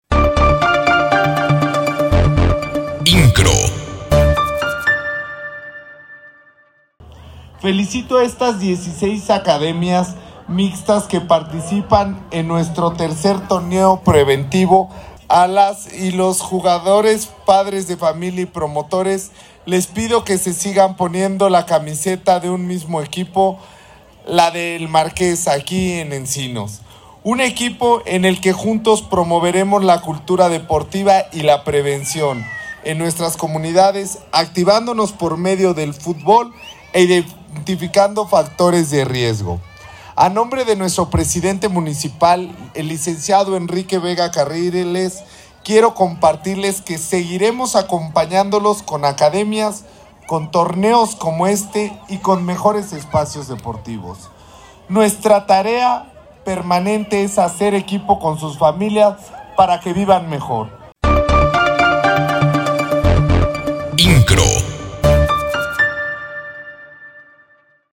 AUDIO-Rodrigo Monsalvo encabezó la inauguración del “Tercer Torneo Preventivo Centro Vive